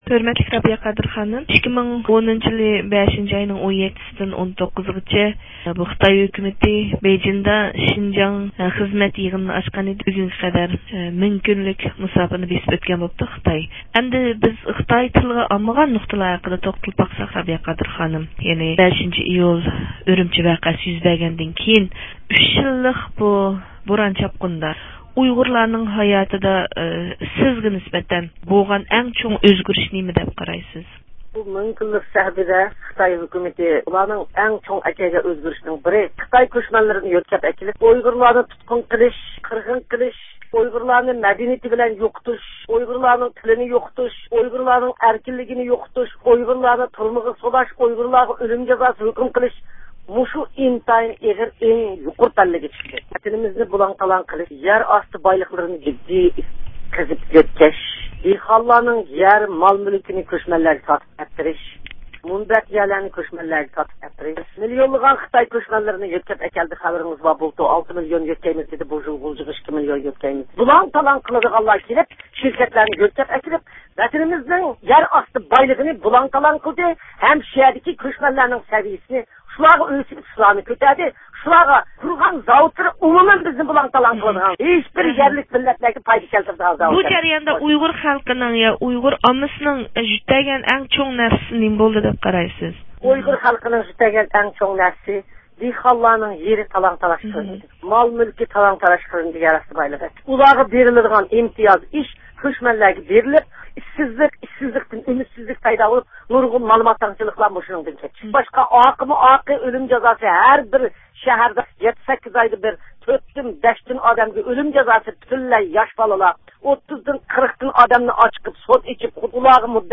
ئۇيغۇر مىللىي ھەرىكىتى رەھبىرى رابىيە قادىر خانىم بۇ ھەقتە مۇخبىرىمىزنىڭ زىيارىتىنى قوبۇل قىلىپ، خىتاي شىنجاڭ خىزمەت يىغىنى ئېچىلغان مىڭ كۈندىن بۇيان تىلغا ئالمىغان، ئەمما ئاشكارا نۇقتىلارنى يورۇتۇپ بەردى.